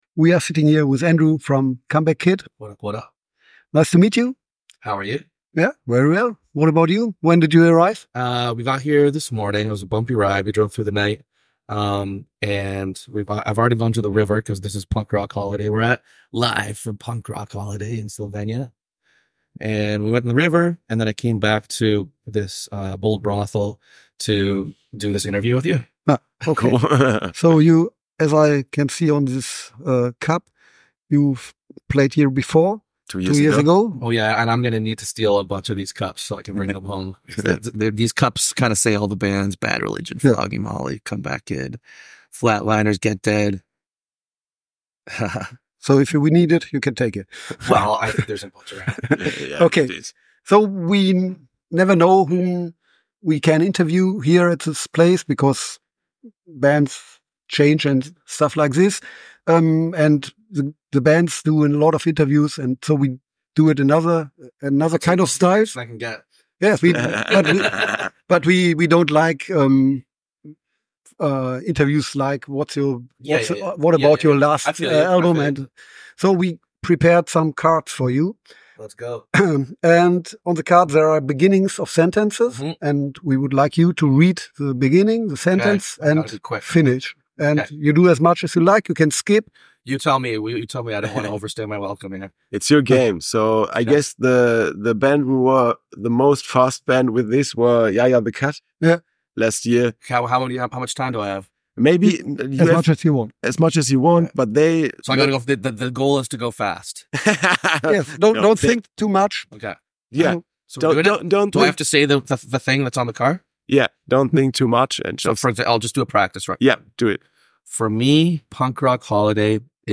Interview @ Punk Rock Holiday 2.4 – Comeback Kid
interview-punk-rock-holiday-24-comeback-kid.mp3